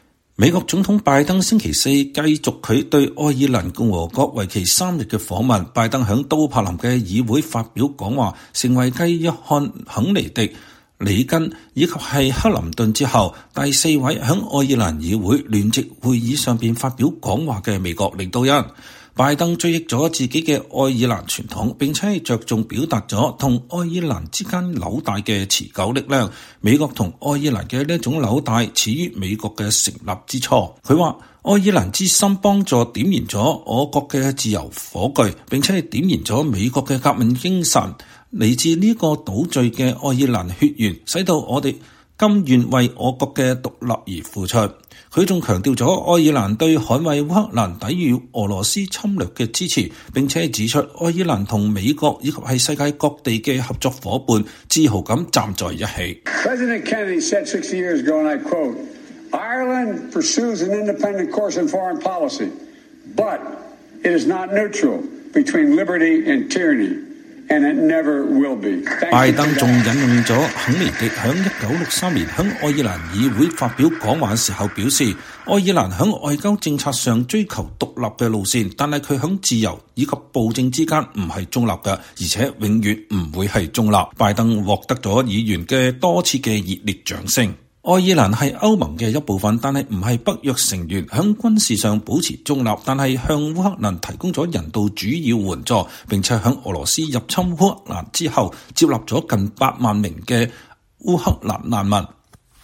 美國總統拜登在愛爾蘭議會發表講話 強調“持久”的兩國關係
拜登獲得了議員們多次熱烈的掌聲。